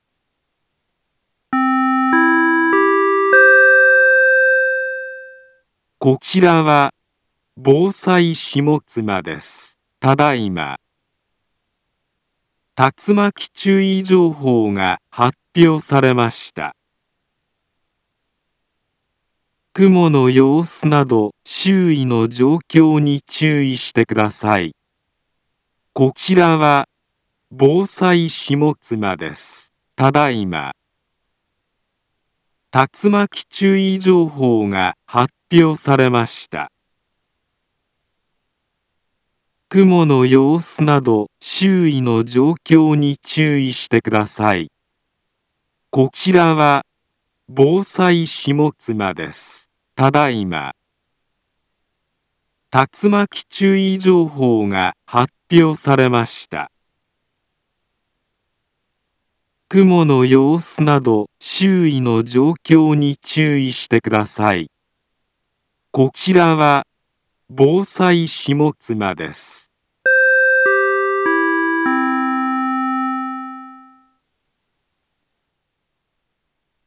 Back Home Ｊアラート情報 音声放送 再生 災害情報 カテゴリ：J-ALERT 登録日時：2021-06-08 17:55:11 インフォメーション：茨城県南部は、竜巻などの激しい突風が発生しやすい気象状況になっています。